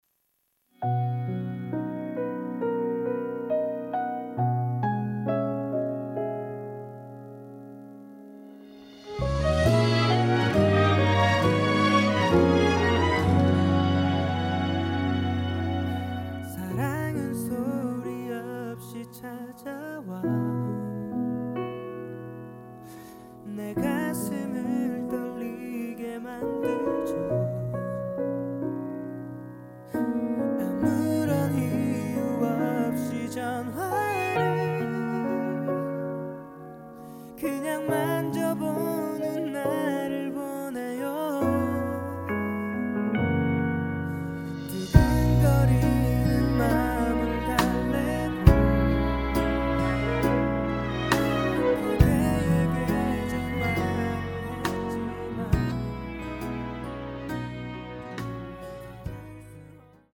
음정 원키 3:45
장르 가요 구분 Voice Cut
Voice Cut MR은 원곡에서 메인보컬만 제거한 버전입니다.